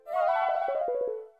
Menu Select.wav